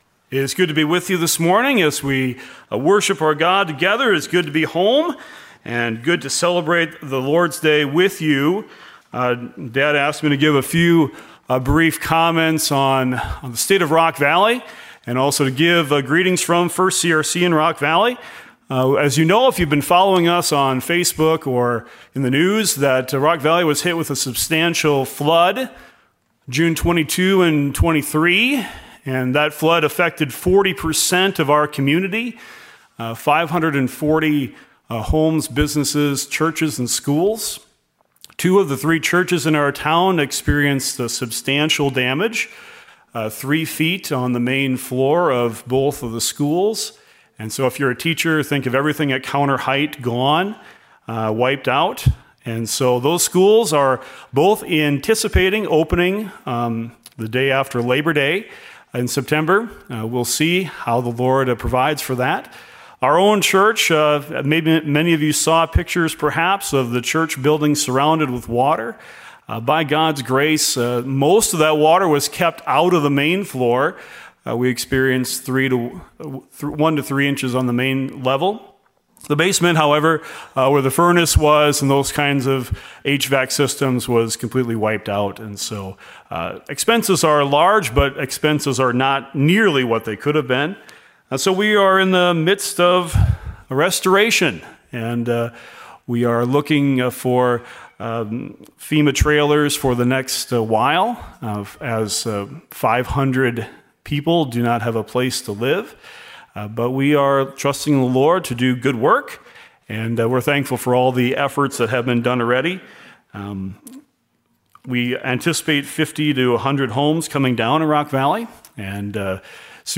God Is Good! Guest preacher